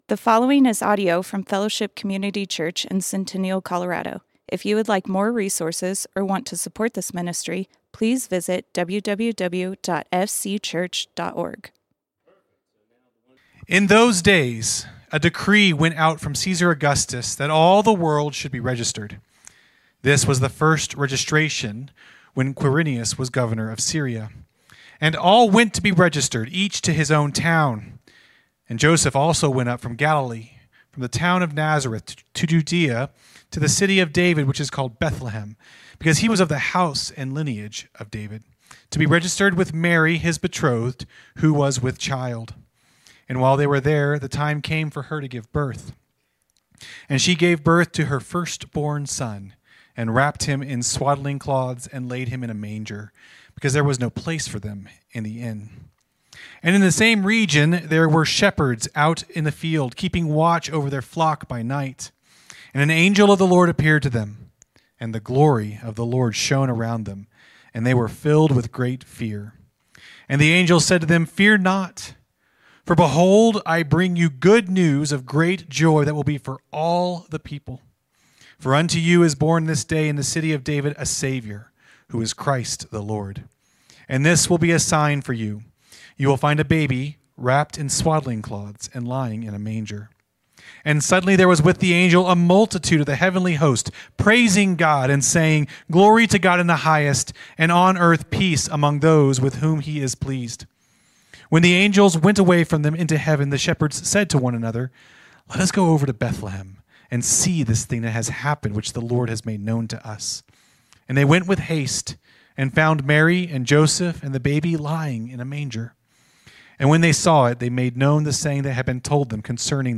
Fellowship Community Church - Sermons One and Only Son Play Episode Pause Episode Mute/Unmute Episode Rewind 10 Seconds 1x Fast Forward 30 seconds 00:00 / 16:48 Subscribe Share RSS Feed Share Link Embed